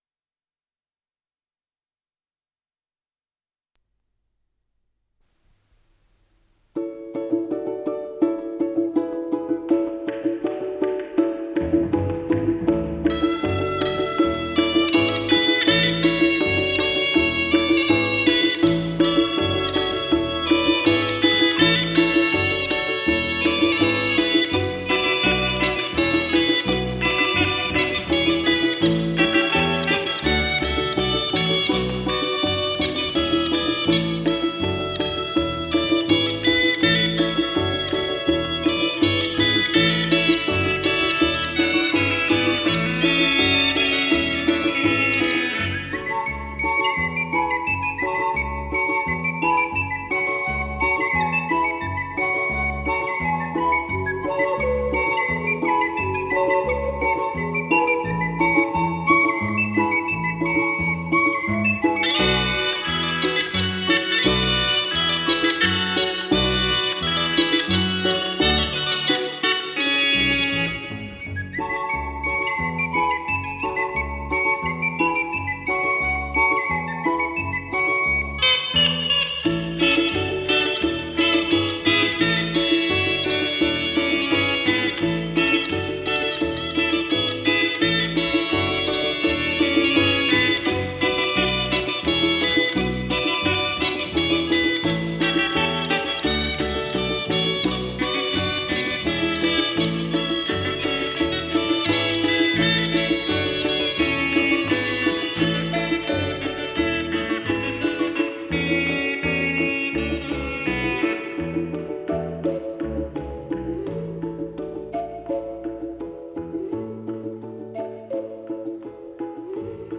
Скорость ленты38 см/с